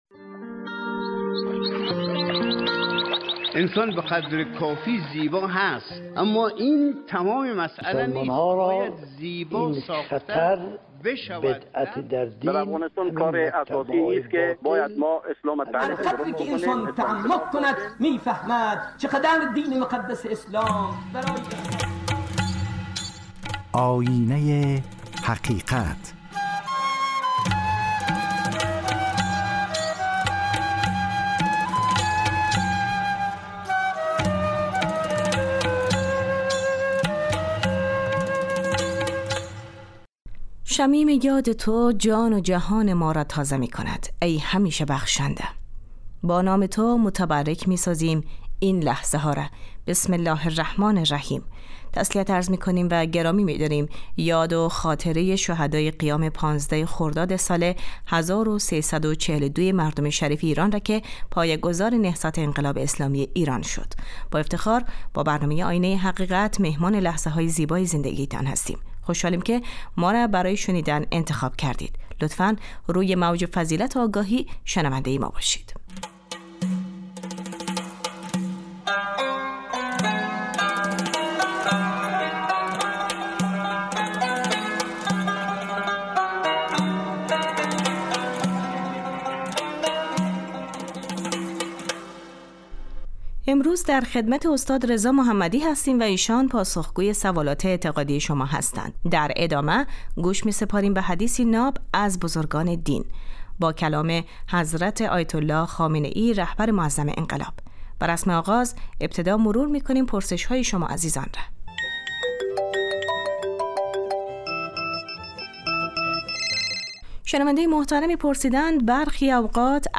زمانی برای تفکر و آگاهی و راهی برای شناخت حقیقت ، برنامه ای به مدت 20 دقیقه برای پاسخگویی به سوالات اعتقادی و معارف اسلامی ؛ شنبه تا پنج شنبه ساعت 12:35 از...
شرح حدیثی نیز با صدای رهبر معظم انقلاب حضرت آیت الله خامنه ای زینت بخش برنامه خواهد بود .